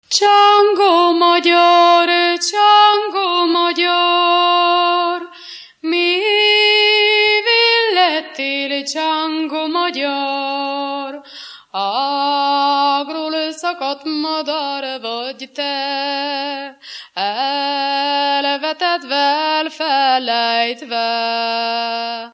Moldva és Bukovina - Moldva - Pusztina
ének
Műfaj: Csángó himnusz
Stílus: 3. Pszalmodizáló stílusú dallamok
Szótagszám: 8.8.8.8
Kadencia: 5 (b3) 1 1